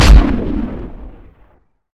Rifle3.ogg